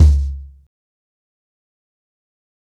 CornerBoy-90BPM.33.wav